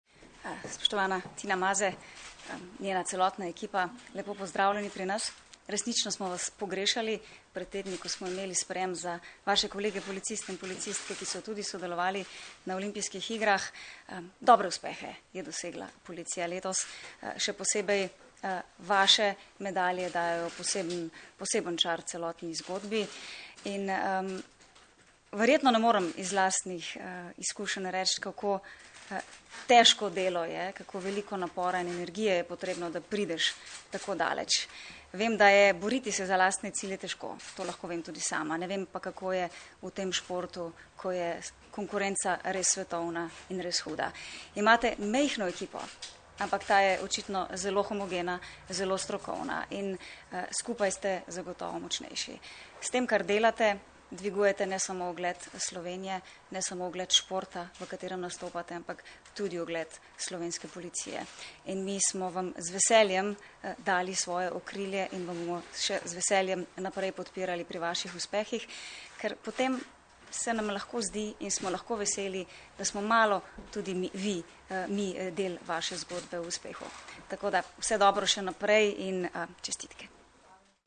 Zvočni posnetek izjave Katarine Kresal (mp3)